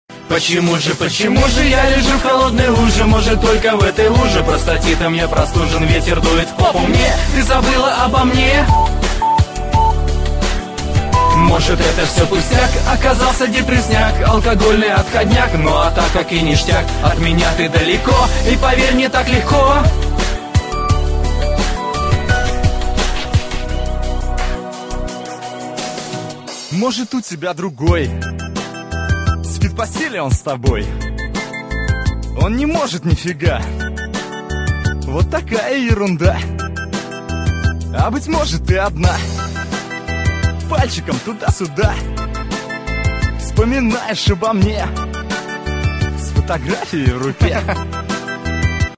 Пародия на всем известную песню